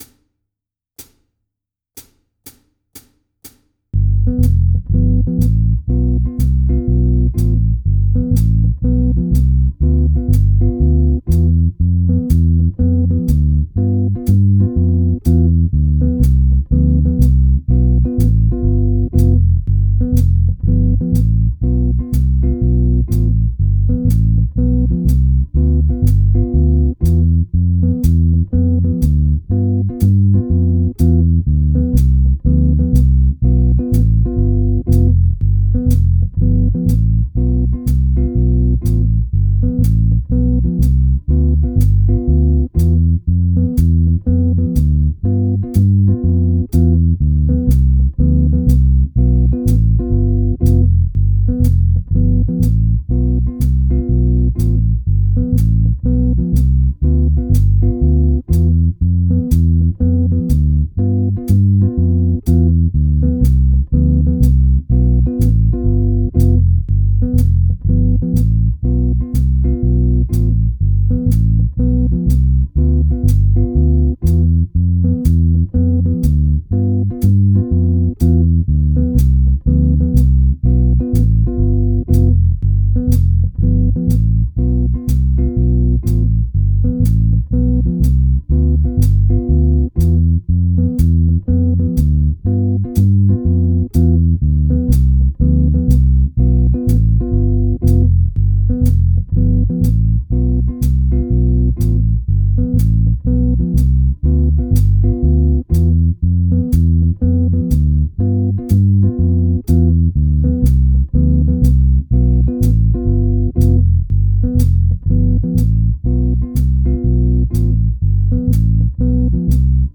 EXAMPLE 2 – This is the backing track that you can use to improvise – it’s got a metronome on 2 & 4, a walking bass line, and some chords!
Backing-Track.wav